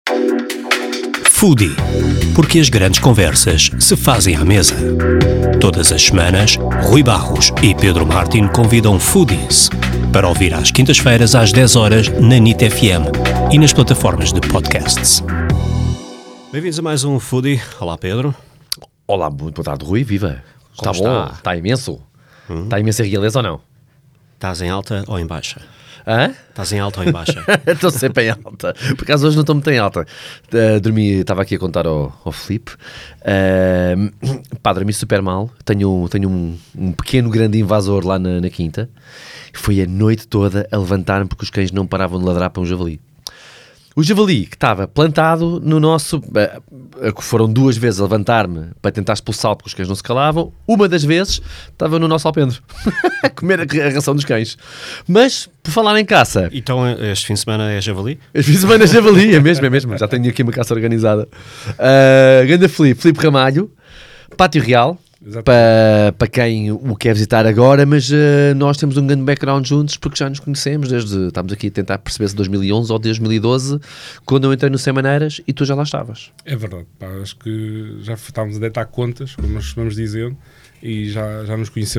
Neste episódio recebemos o Chef Chakall, e se há boas conversas para ter à mesa, esta é uma delas!